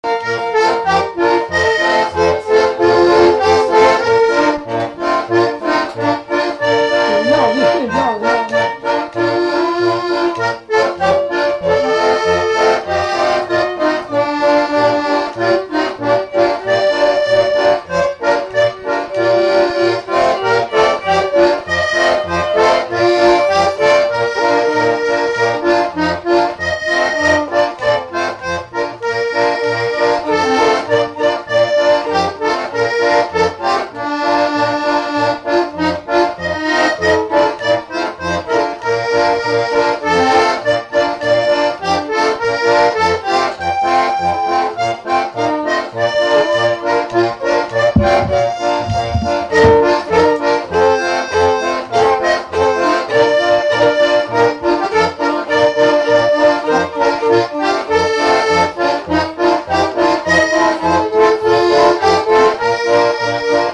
Marche
Résumé Instrumental
Catégorie Pièce musicale inédite